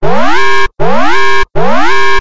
factory_alarm_2.ogg